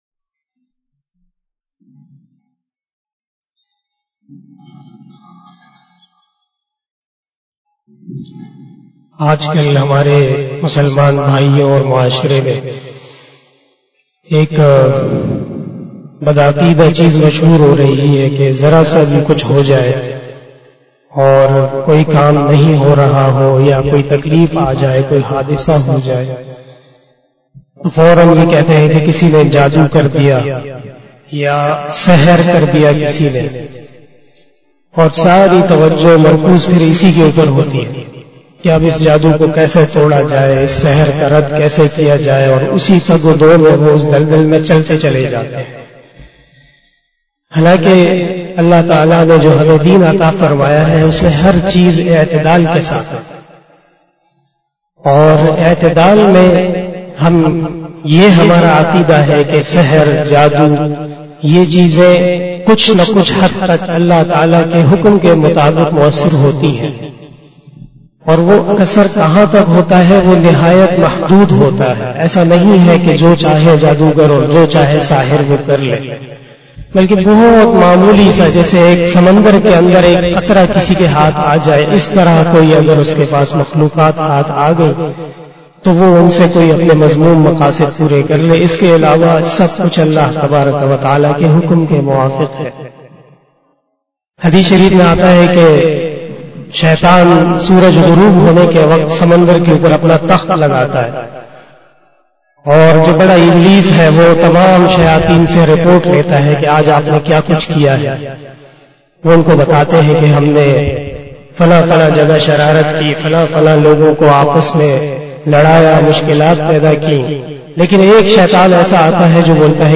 057 After Asar Namaz Bayan 19 Setember 2021 (11 Safar 1443HJ) Sunday